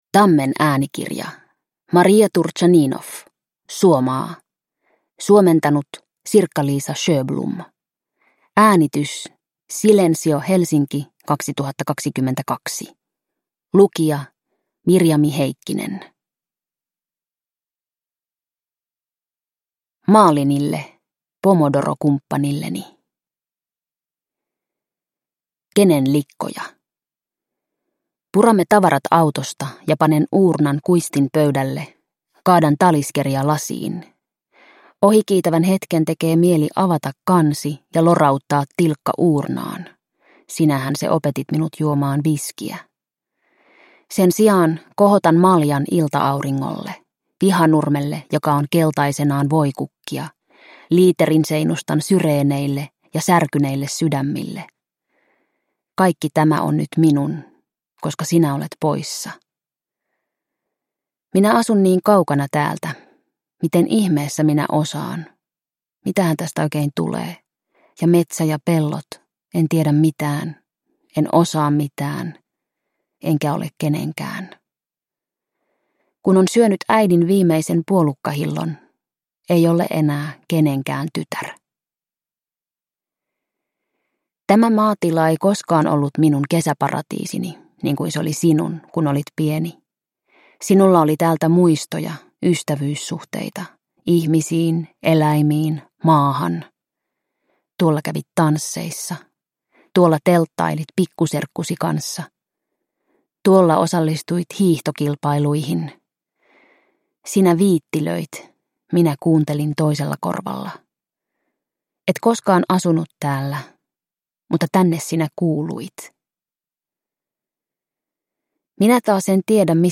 Suomaa – Ljudbok – Laddas ner